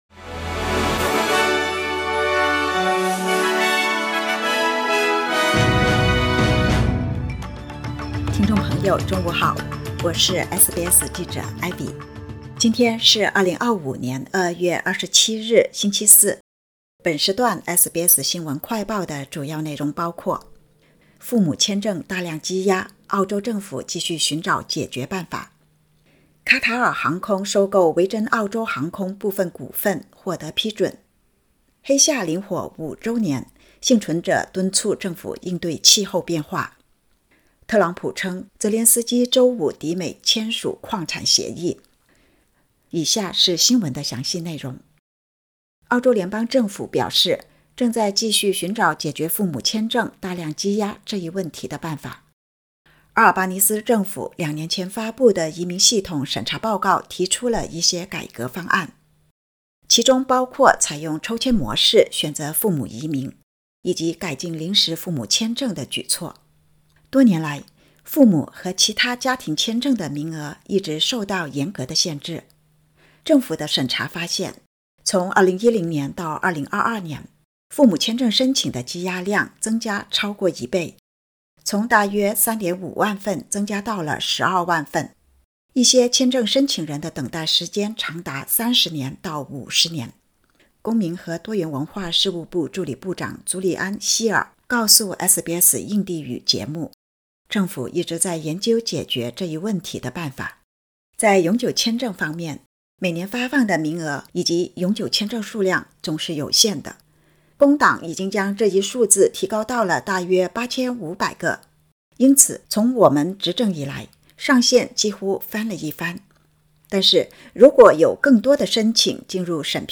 【SBS新闻快报】父母签证大量积压 澳洲政府继续寻找解决办法